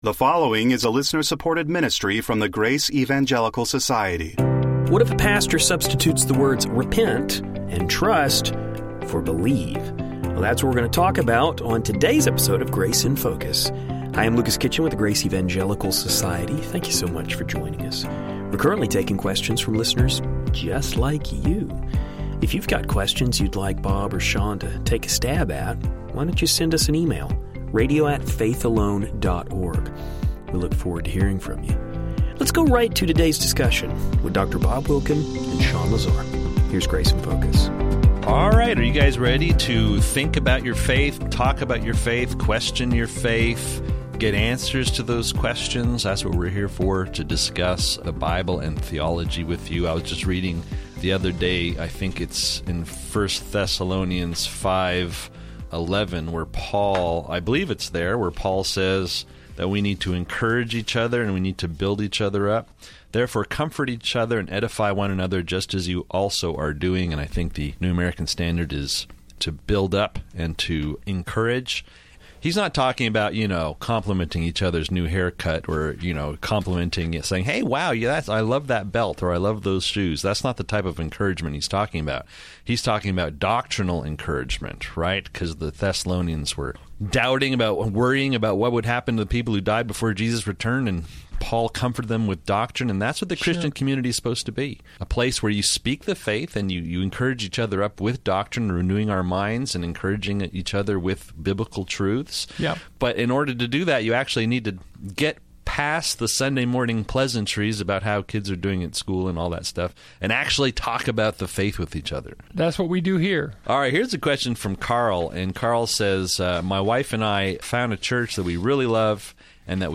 The guys will unpack these terms and hopefully provide some clarity. In addition, we will hear the guys discuss the Pope and his view on salvation.